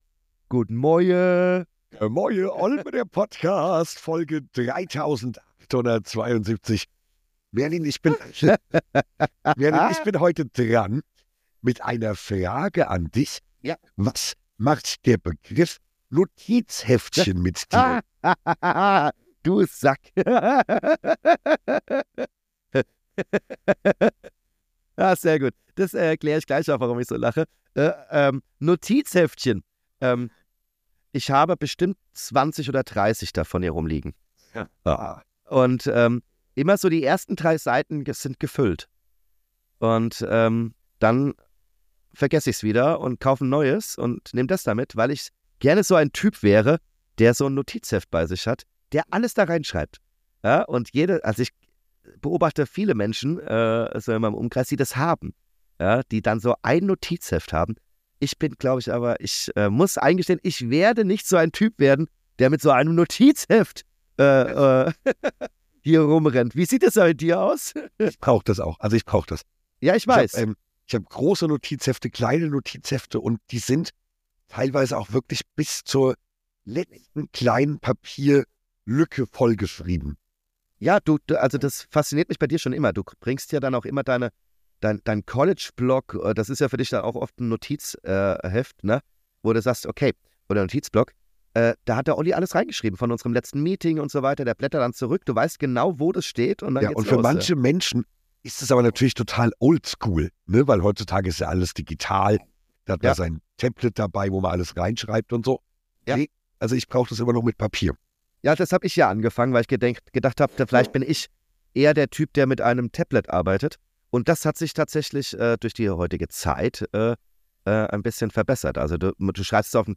Eine neue Folge wie Schmetterlinge im Bauch - von Mehlwürmern bis hin zu Hamsterbacken haben wir die Tierwelt gemeinsam durchlaufe, wie immer unge(schnibbelt)schnitten und roh.